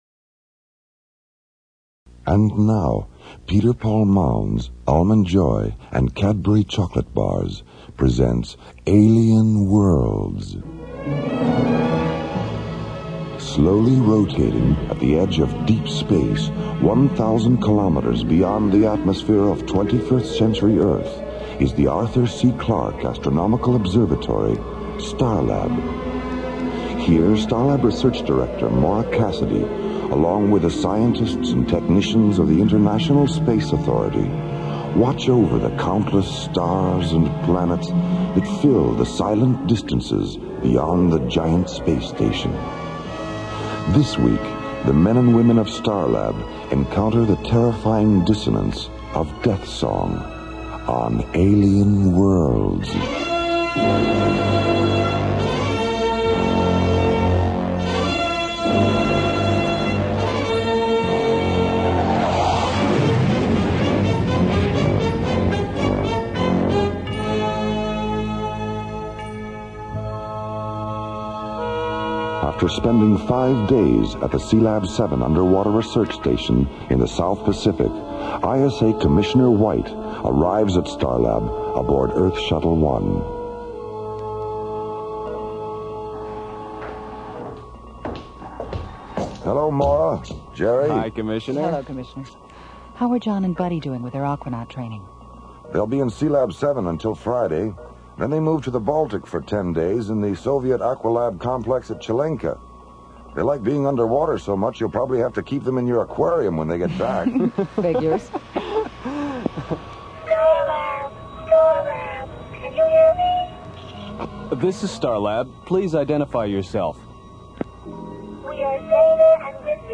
'Alien Worlds' was a syndicated radio show that brought together a blend of captivating narratives, realistic sound effects, and high production values, setting a new standard for audio drama.
The show was ahead of its time, utilizing a documentary style of dialogue that immersed listeners in its interstellar adventures. Each episode transported the audience to different corners of the galaxy, exploring complex themes and introducing memorable characters.